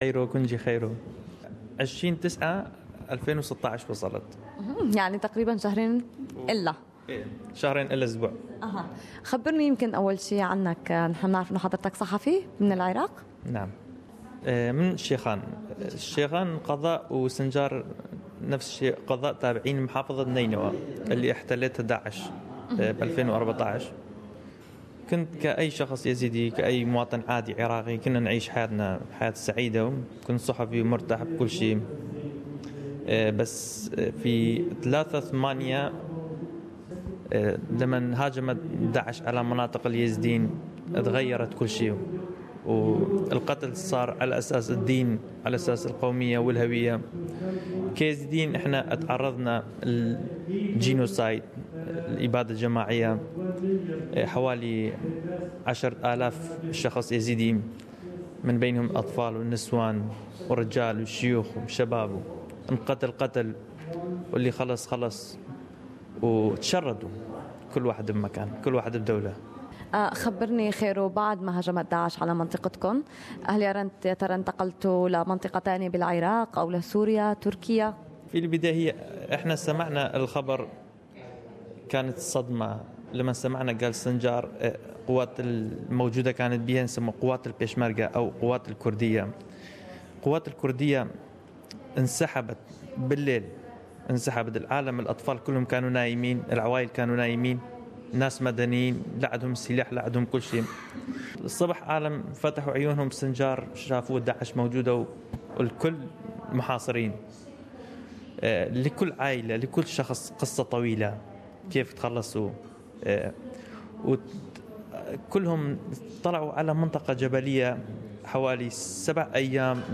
حواراً